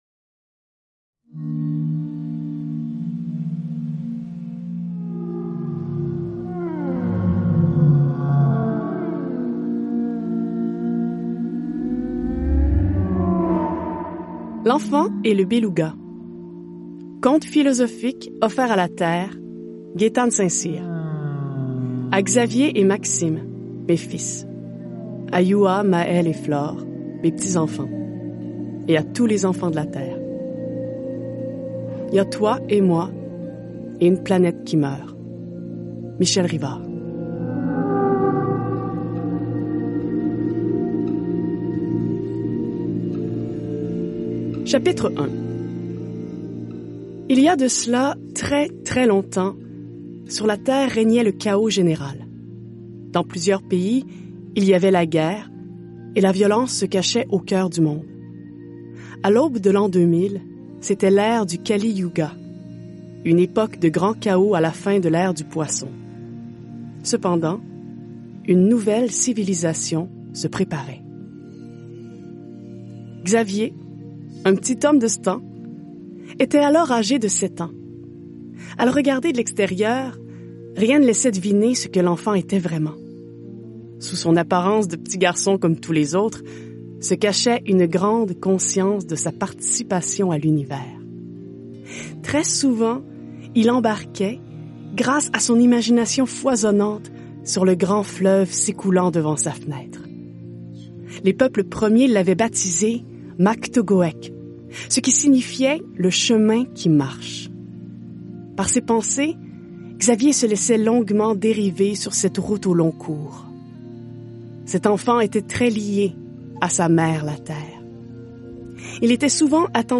Téléchargez le livre audio, pdf ou epub et faites-en la lecture librement.